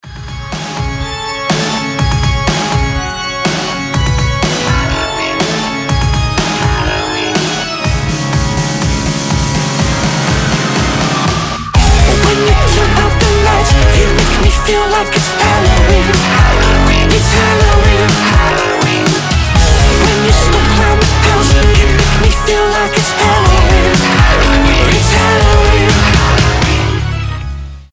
alternative rock
pop rock , мрачные , орган